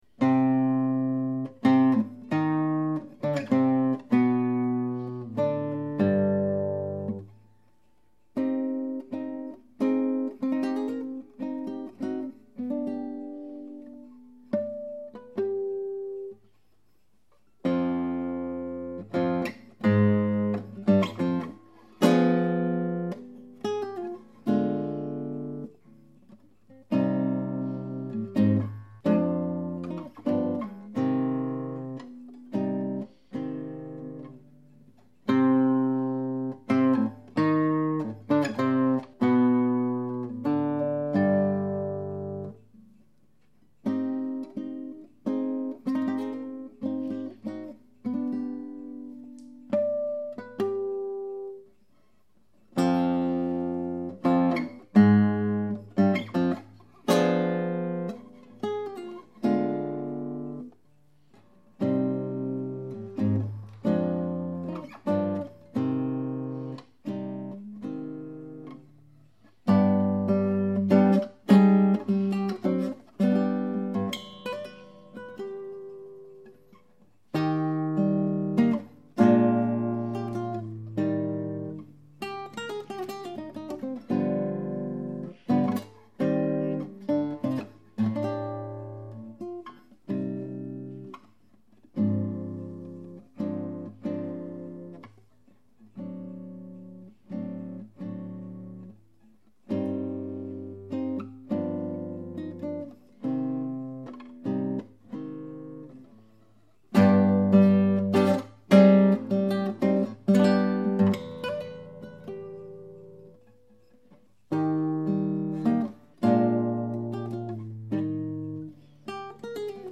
[mp3] F.Sor - Fantaisie opus 7 Largo ma non tanto - Guitare Classique
A peine mieux que l'ancien sinon le son et les trilles mais toujours aussi laborieux.
Je me rends compte que je ne joue jamais au même tempo, celui-là c'est le plus lent.
c'est un long morceau qui paraît dur !! des passages difficiles où tu t'en sors bien 1'30
Cette pièce que j'adore, particulièrement difficile, est drolement bien menée jusqu'au bout ... de très beaux moments, quelques précipitations dans les ornements ( tu as le temps dans une fantaisie )mais au bout du compte une belle homogénéité dans le tempo ( qui pourrait être m^me un peu plus lent ).